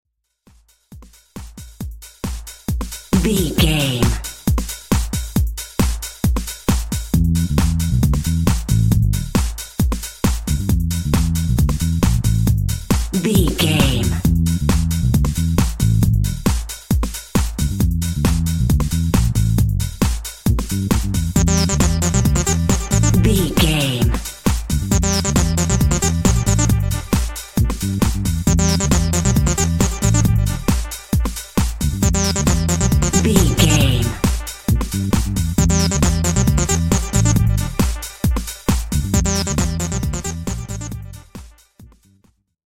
Electro Bass Funky Groove.
Aeolian/Minor
Drum and bass
break beat
electronic
sub bass
drums
synth
drum funk